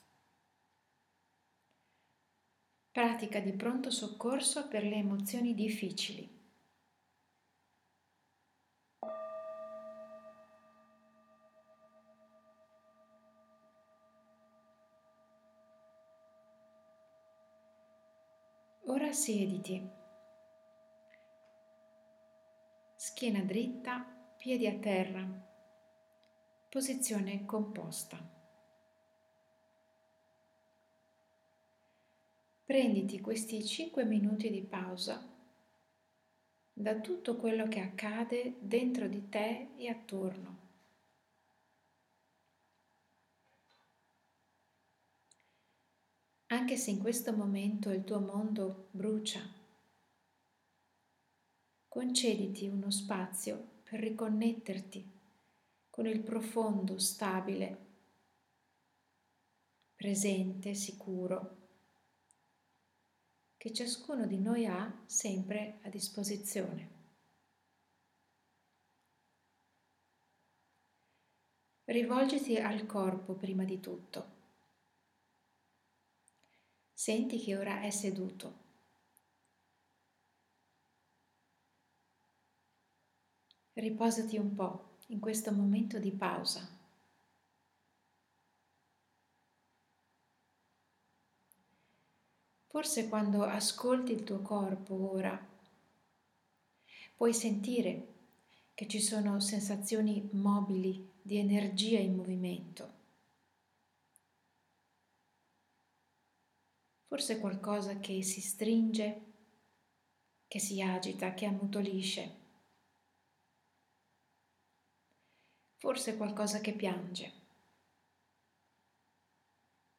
Ti lascio qui una breve pratica di mindfulness di pronto soccorso emozioni difficili.
pratica-emozioni-difficili-pronto-soccorso.m4a